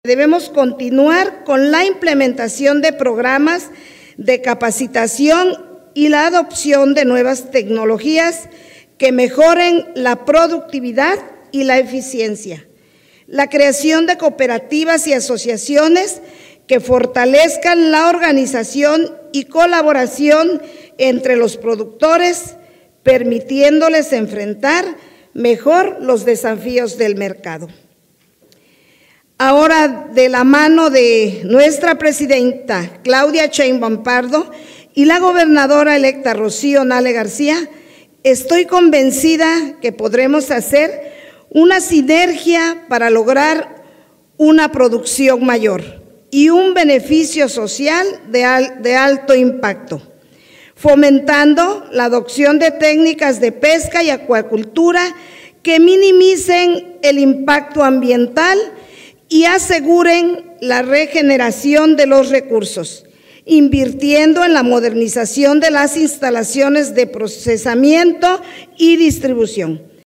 Al hacer uso de la tribuna, la legisladora recordó que el estado cuenta con 720 kilómetros de litoral, lo que representa que estas prácticas sean de gran importancia como sustento alimentario, económico y comercial, toda vez que, además de generar empleos directos e indirectos, en comunidades costeras y rurales, promueven la estabilidad social al mantener tradiciones y prácticas culturales.